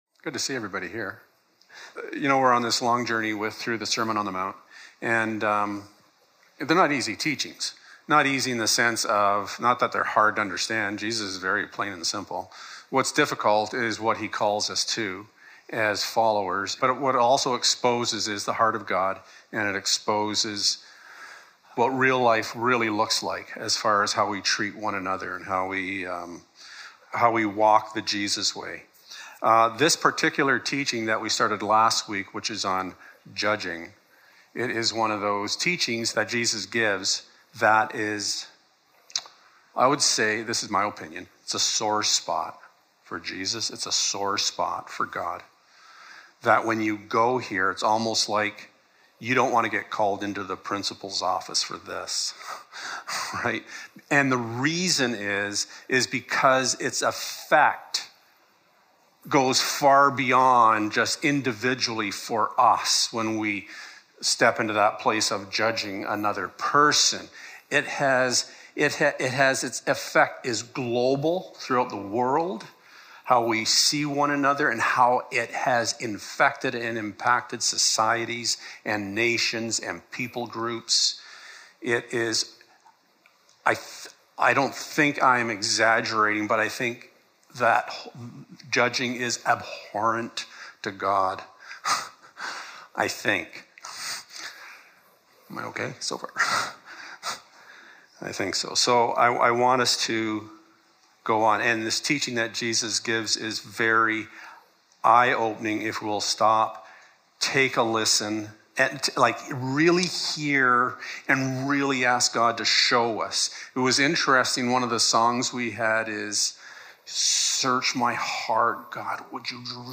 1-5 Service Type: Sunday Morning Words matter